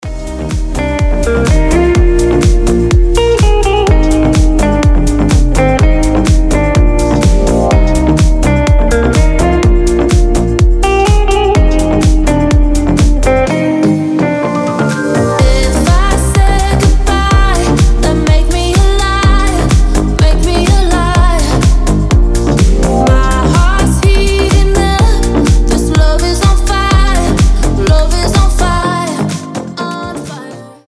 мужской голос
громкие
deep house
мощные басы
Brazilian bass
Отличный клубный рингтон